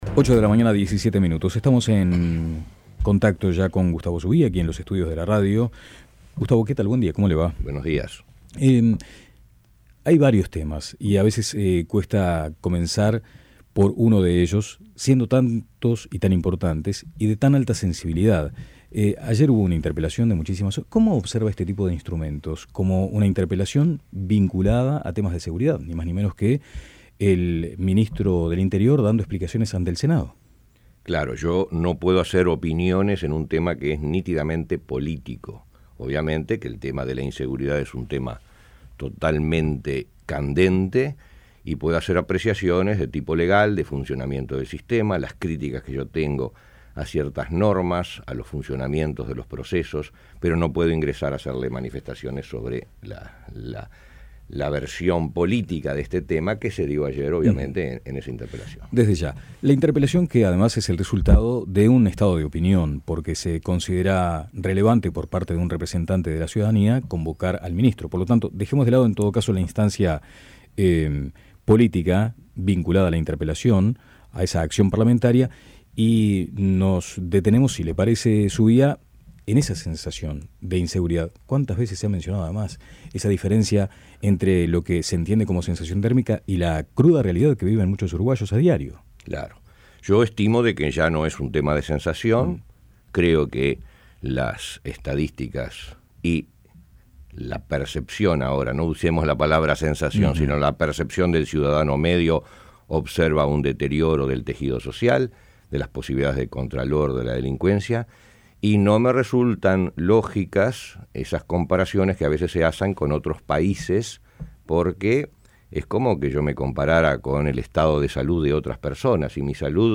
Entrevista al fiscal Gustavo Zubía